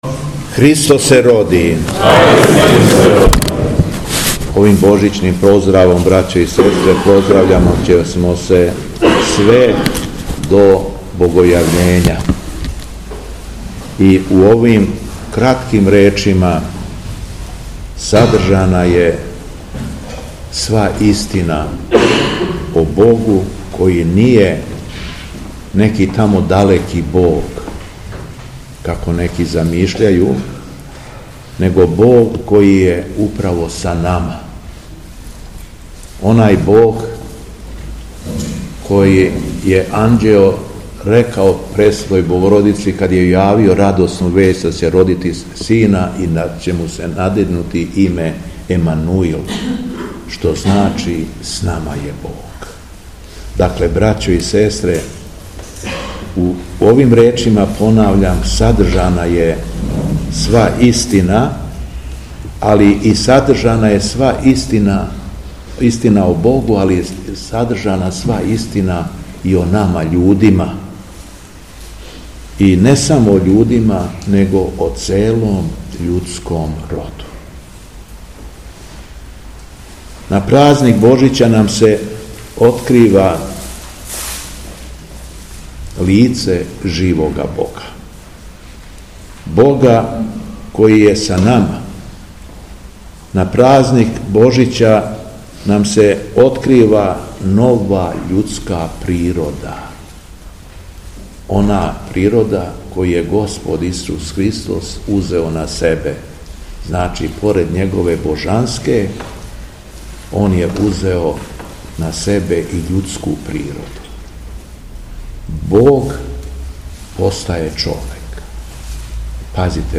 Беседа Његовог Високопреосвештенства Митрополита шумадијског г. Јована
Након прочитаног празничног Јеванђеља верном народу се надахнутом беседом обратио Митрополит Јован: